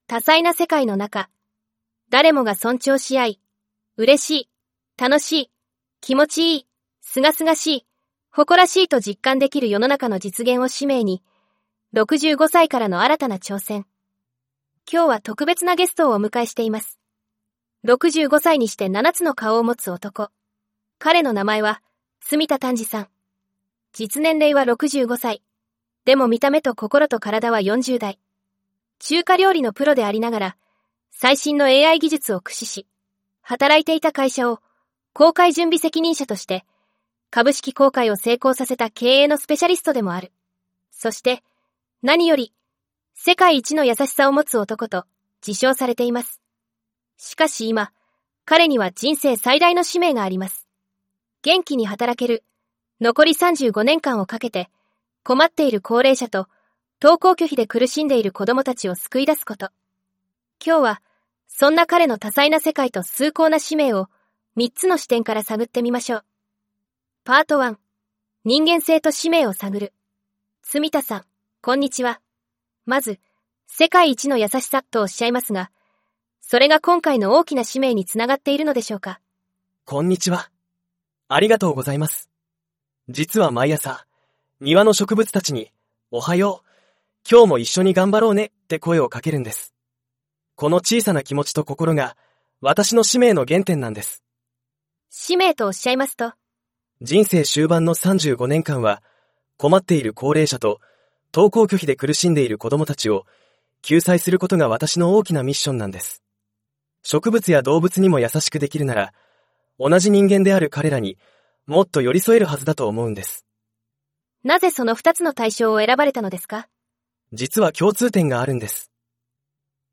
【オープニング音楽：明るく希望に満ちたメロディ】
【音楽フェードアウト】